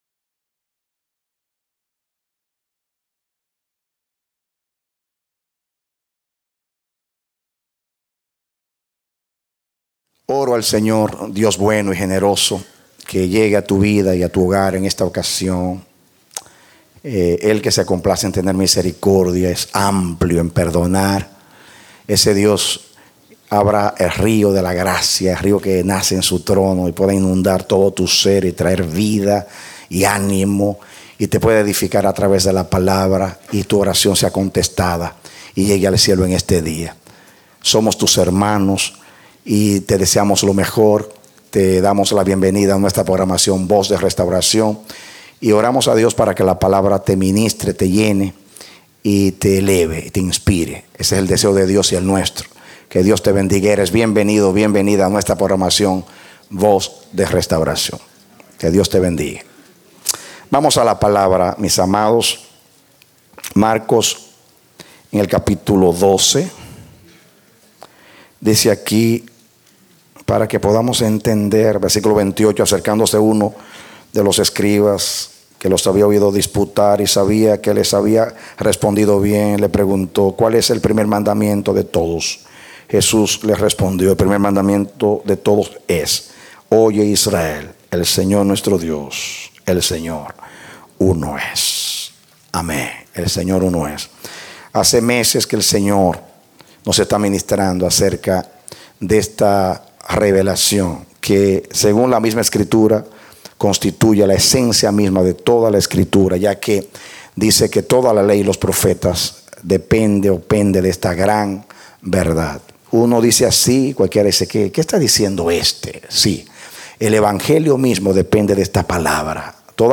A mensaje from the serie "Nuestro Dios Uno es."